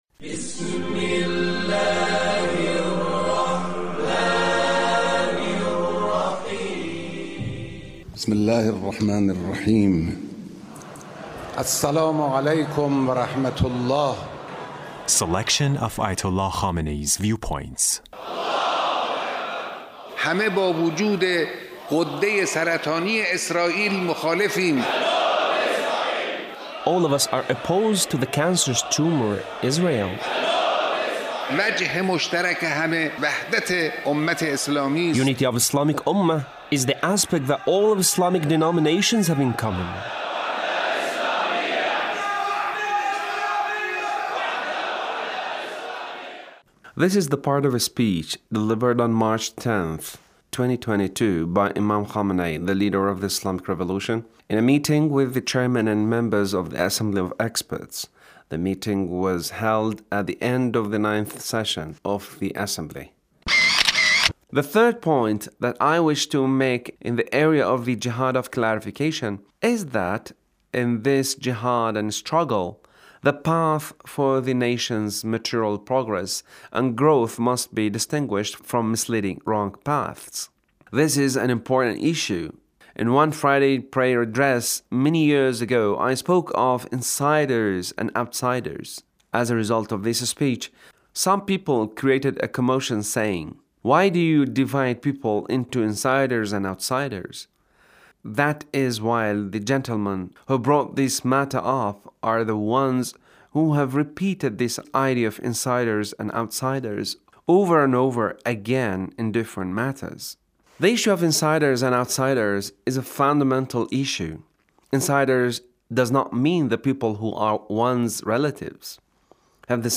The Leader's speech on Assembly of Experts